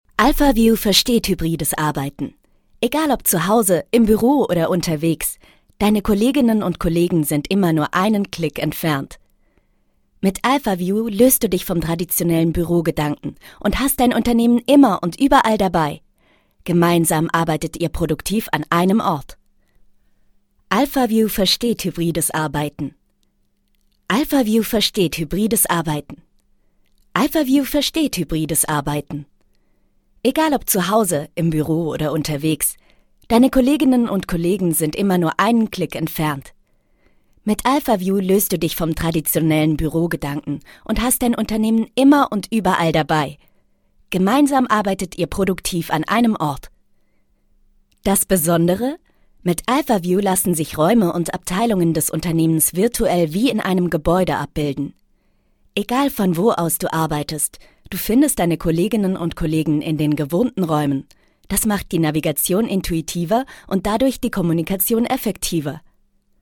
Female
Werbung Eiscreme
Stimmvielfalt